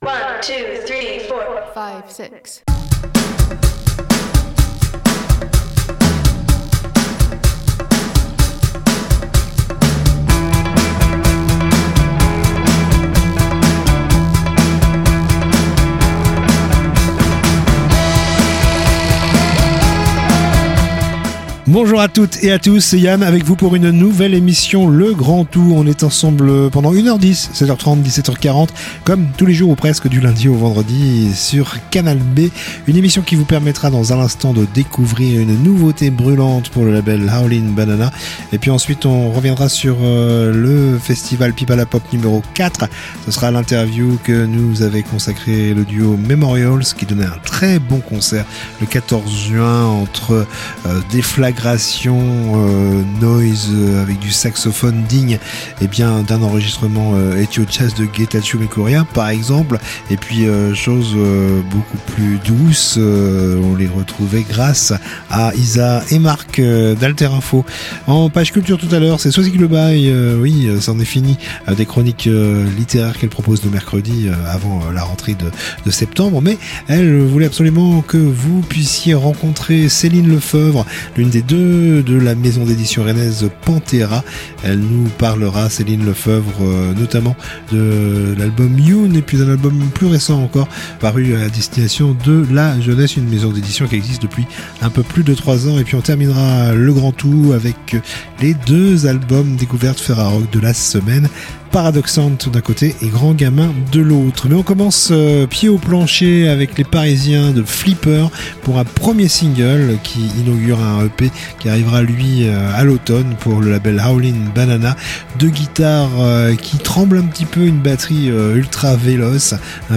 Discussion enregistrée le 14/06 au Festival Pies Pala Pop # 4 .